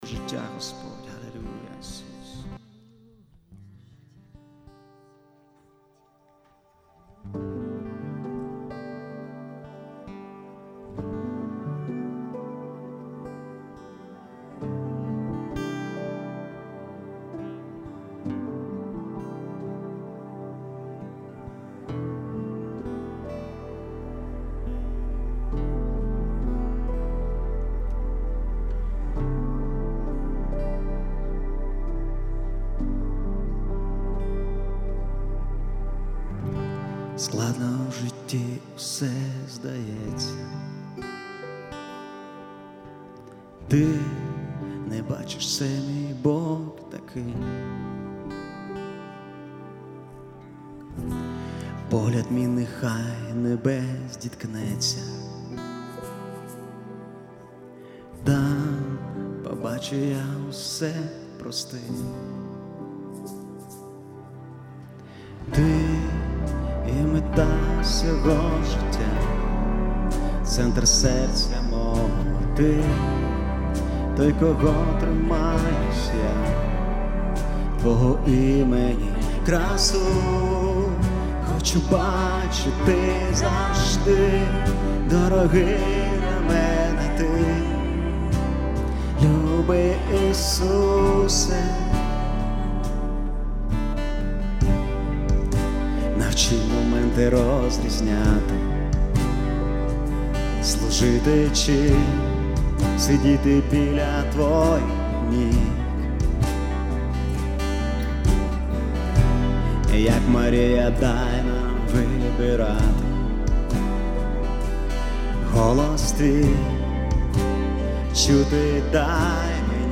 ( тільки клава )
( + акустика перебір)
( + бас на подвісах, + ел. гіт.)
( + барабани)
442 просмотра 490 прослушиваний 3 скачивания BPM: 130